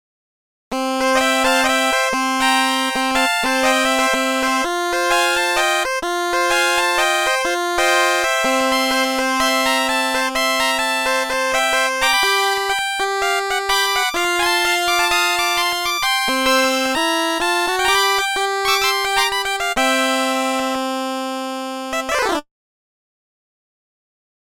06-Stylophone
06-Stylophone.mp3